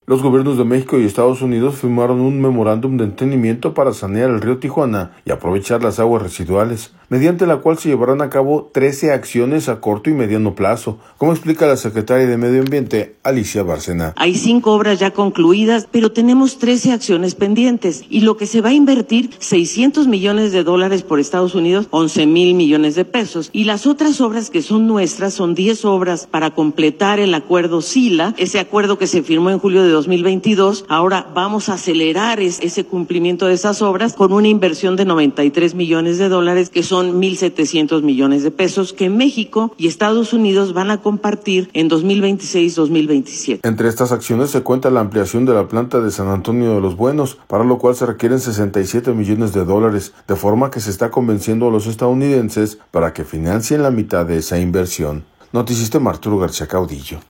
audio Los gobiernos de México y Estados Unidos firmaron un memorándum de entendimiento para sanear el Río Tijuana y aprovechar las aguas residuales, mediante la cual se llevarán a cabo 13 acciones a corto y mediano plazo, como explica la secretaria de Medio Ambiente, Alicia Bárcena.